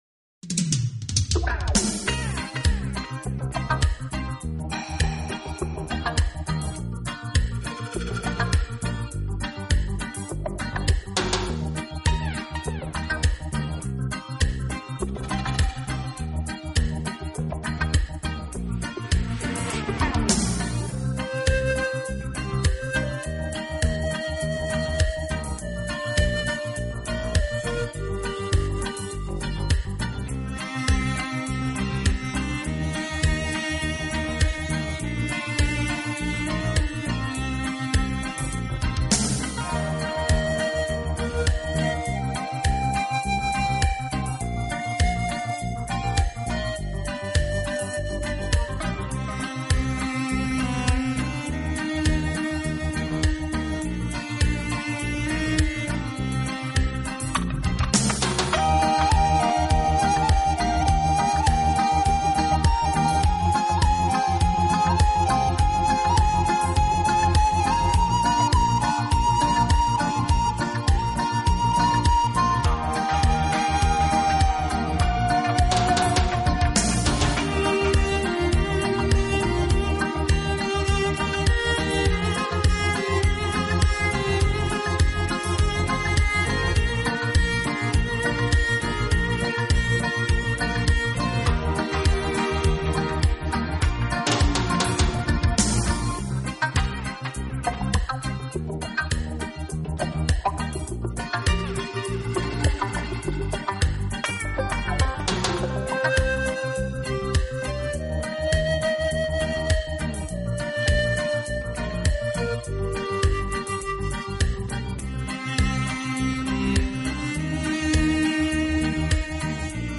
Genre: instrumental/Oldies